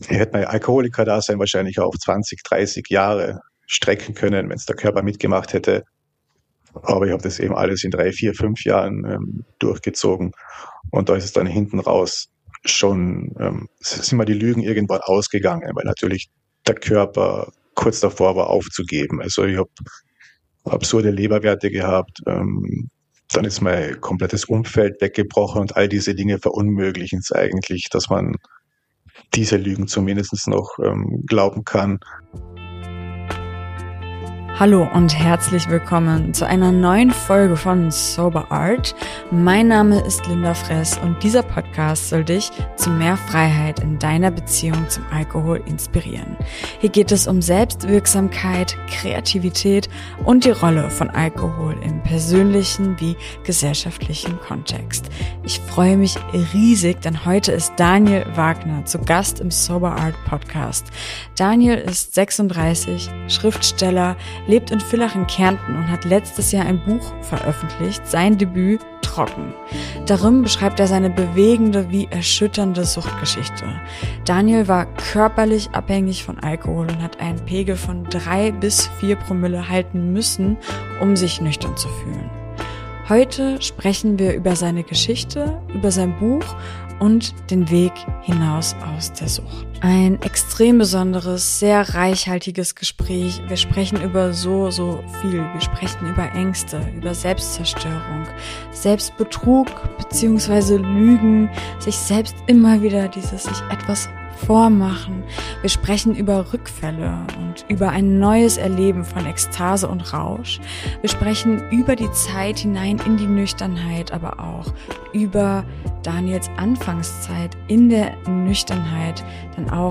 Heute sprechen wir über seine Geschichte, über sein Buch und den Weg hinaus aus der Sucht. Ein reichhaltiges Gespräch über Ängste, Selbstzerstörung und Lügen, über Rückfälle, über ein neues Erleben von Ekstase und Rausch und die erste Zeit der Nüchternheit - denn auch die kann sehr herausfordernd se...